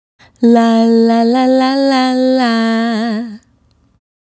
La La La 110-A#.wav